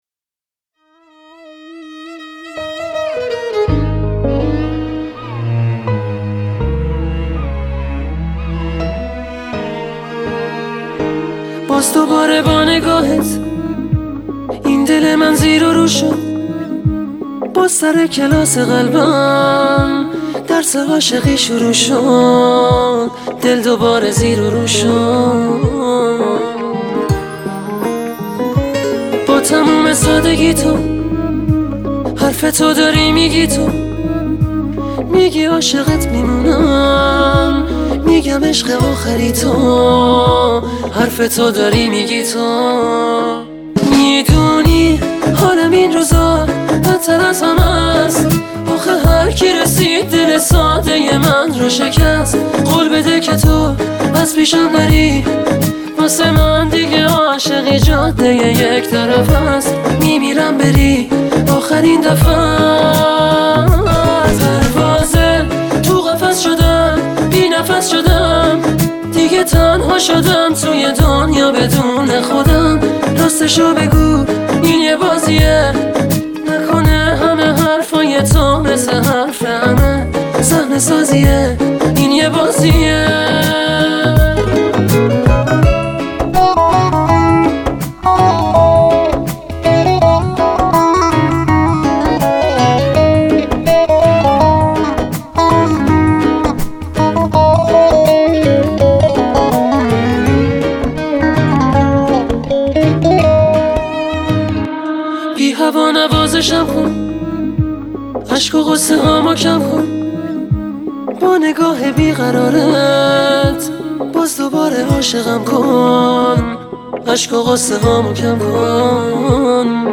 این مجموعه شامل آهنگ های پر طرفدار غمگین و آرام است